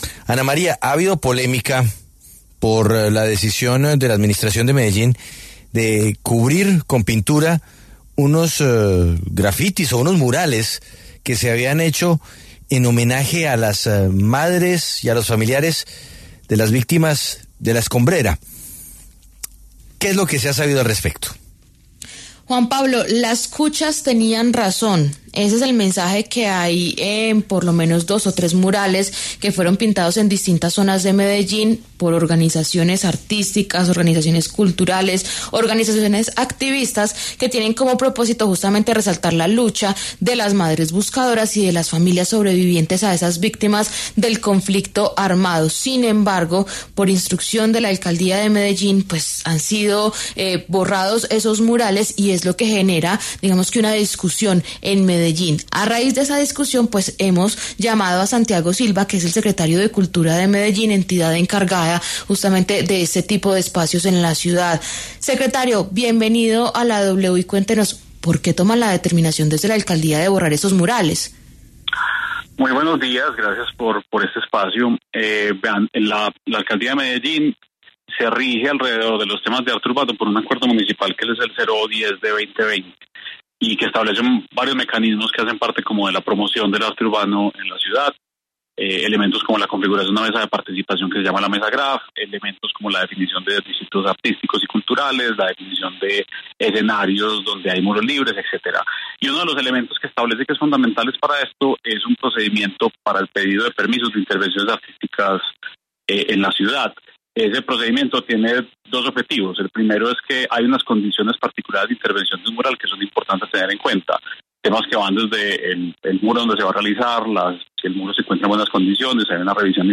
Santiago Silva, secretario de Cultura de Medellín, explicó en La W por qué se tomó la decisión de retirar el mural que contenía el mensaje “Las cuchas tenían razón”.